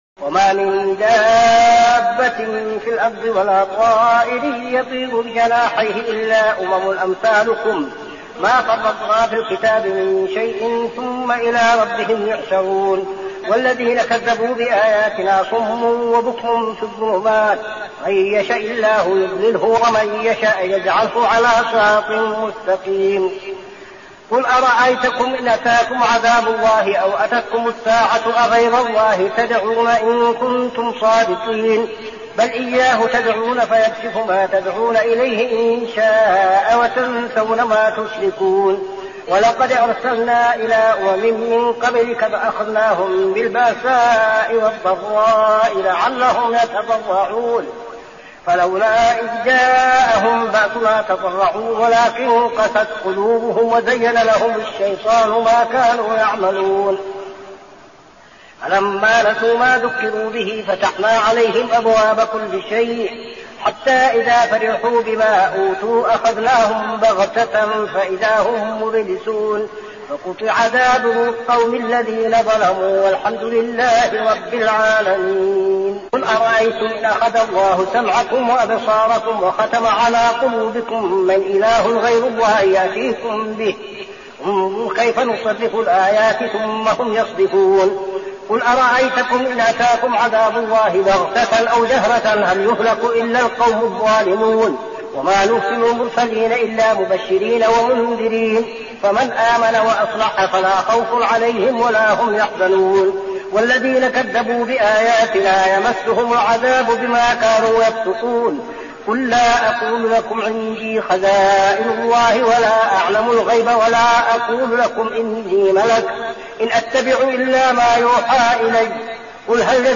صلاة التراويح عام 1402هـ سورة الأنعام 36-110 | Tarawih prayer Surah Al-An'am > تراويح الحرم النبوي عام 1402 🕌 > التراويح - تلاوات الحرمين